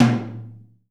TOM 2L.wav